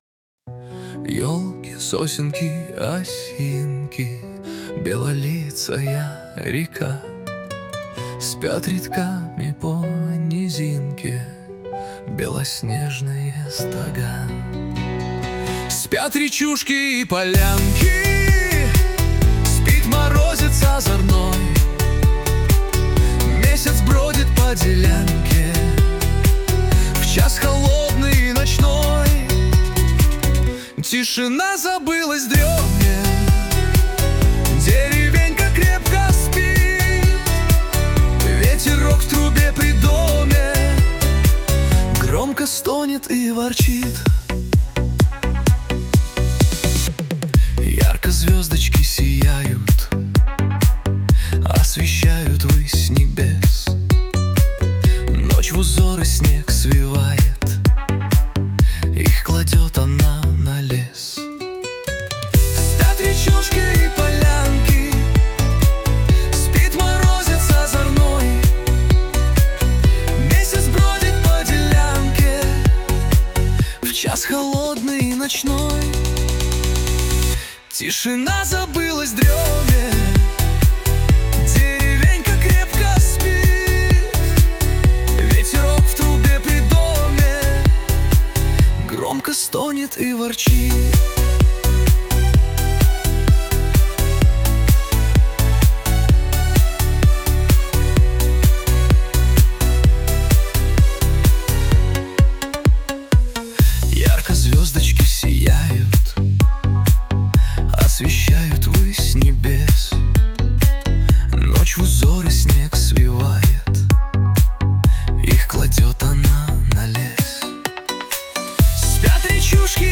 Аудиостихотворение ДЫШАТ НЕЖНЫЕ ПРОСТОРЫ... слушать